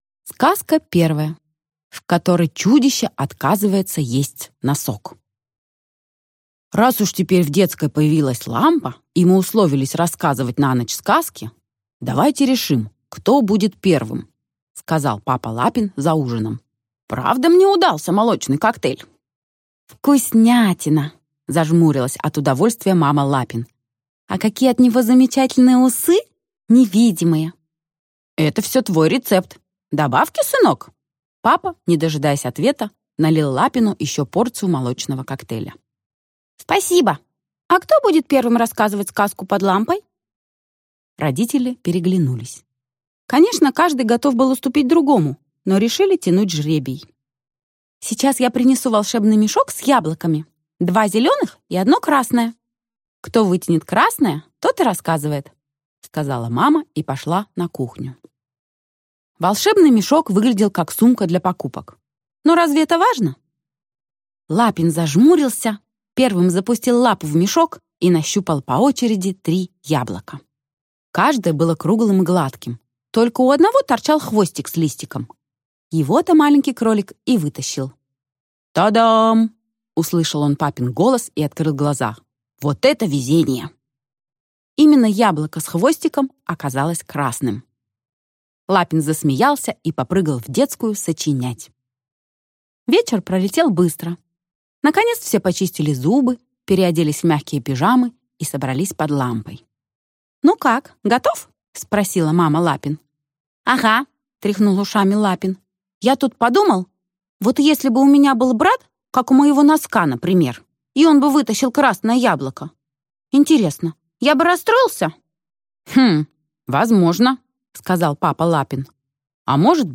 Аудиокнига Лапин. Сказки на ночь | Библиотека аудиокниг